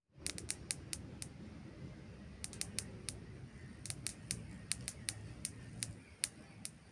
Epinome Cracker (Hamadryas epinome)
Life Stage: Adult
Location or protected area: Parque Nacional Iguazú
Condition: Wild
Certainty: Observed, Recorded vocal